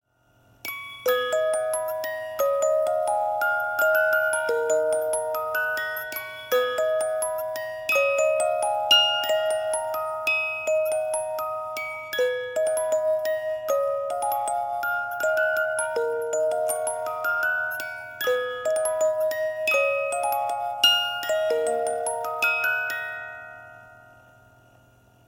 Music box sample